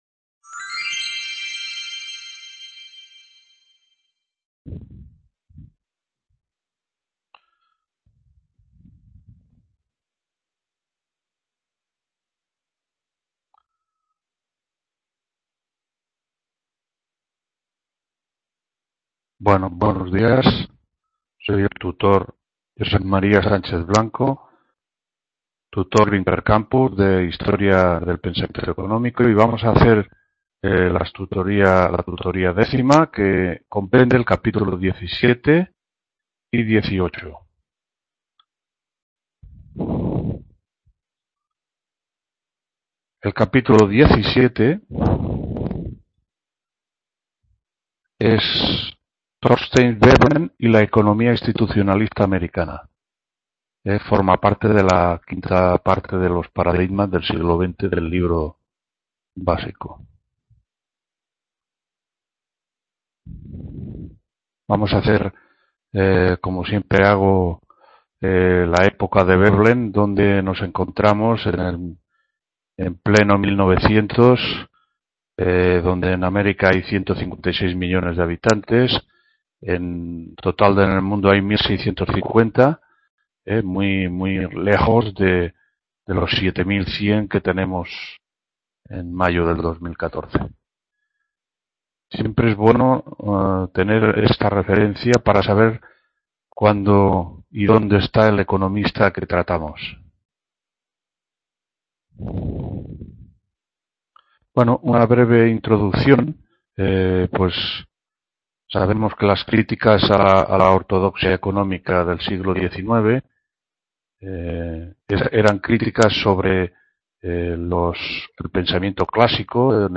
4ª TUTORÍA DICIEMBRE 2014- HISTORIA DEL PENSAMIENTO… | Repositorio Digital